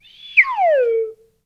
whistle_slide_down_02.wav